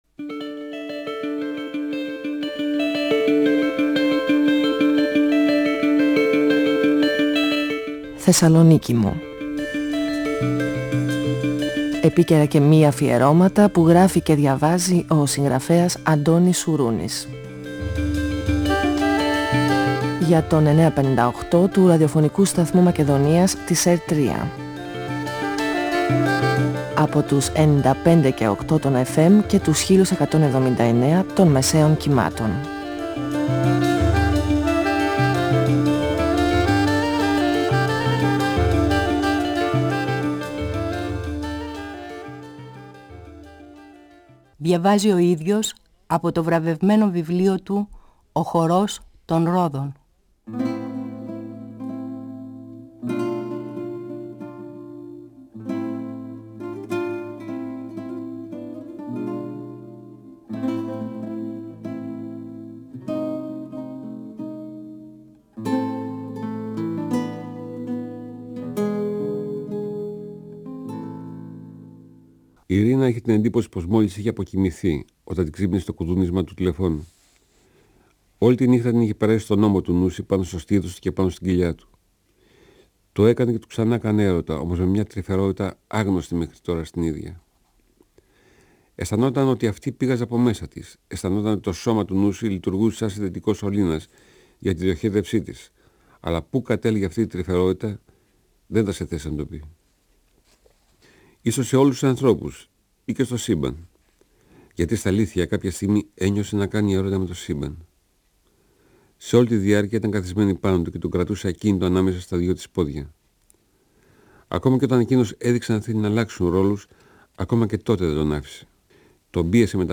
Ο συγγραφέας Αντώνης Σουρούνης (1942-2016) διαβάζει από το βιβλίο του «Ο χορός των ρόδων», εκδ. Καστανιώτη, 1994. Η συνάντηση της Φιλιώς με τον Νάγκελ δεν έχει καλή συνέχεια, γιατί ο Τάκης δολοφονεί τον Νάγκελ και ανατρέπεται η ζωή όλων.